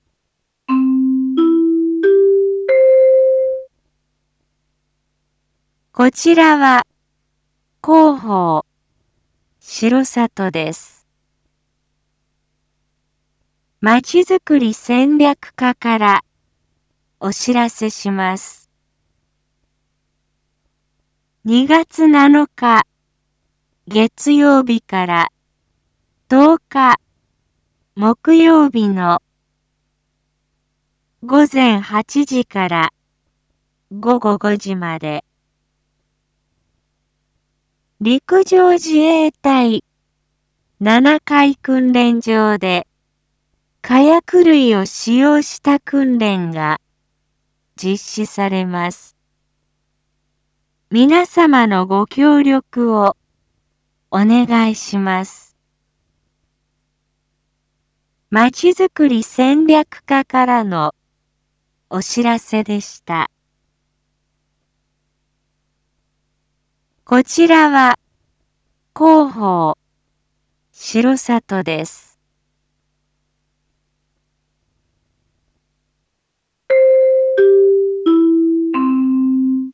一般放送情報
Back Home 一般放送情報 音声放送 再生 一般放送情報 登録日時：2022-02-07 07:01:21 タイトル：R4.2.6 19時放送分 インフォメーション：こちらは広報しろさとです。